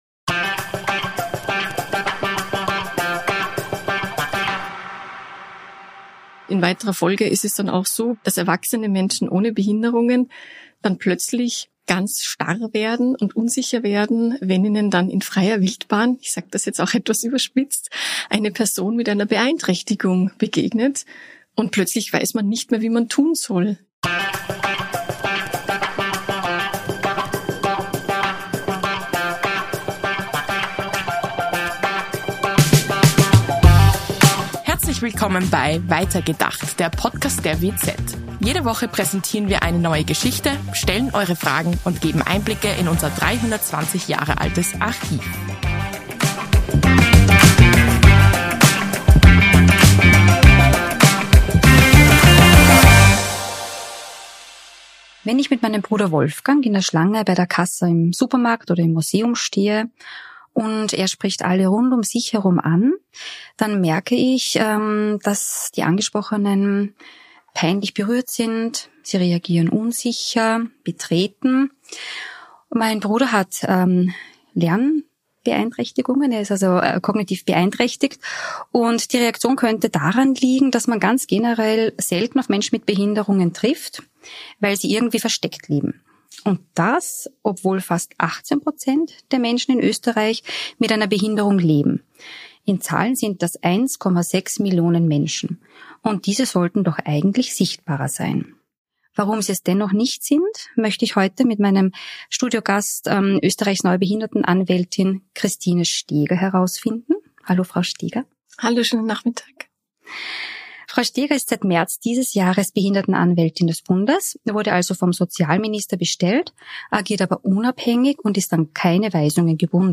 ihren Studio-Gast, die neue Behindertenanwältin Christine Steger